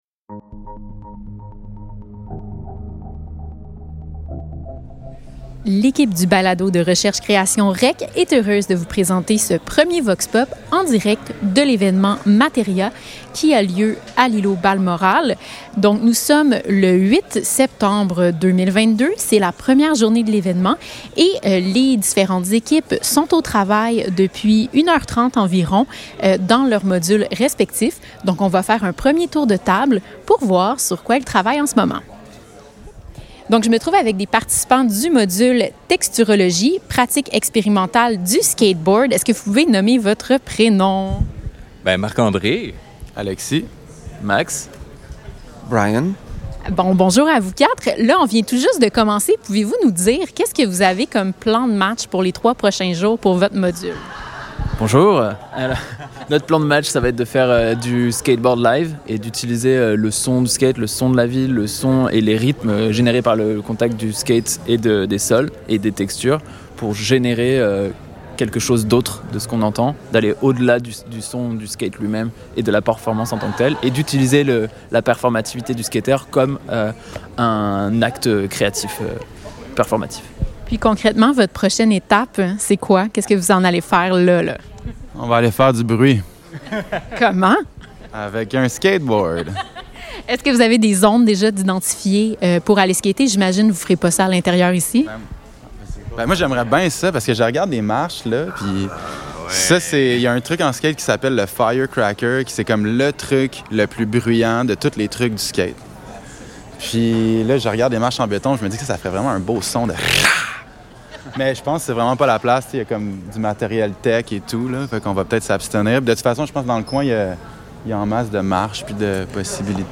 VOX POP REC1 : Début de l'événement MATERIA et rencontre des modules